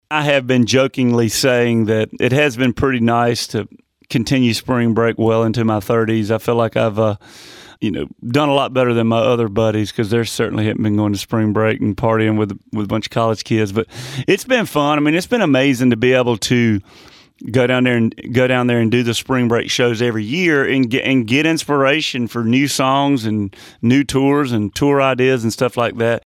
Audio / Luke Bryan says going to Panama City Beach to do his annual Spring Break shows has been such an inspiration on other parts of his career.